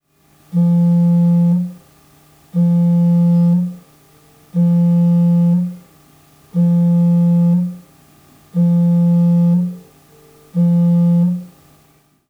Phone_vibrate.wav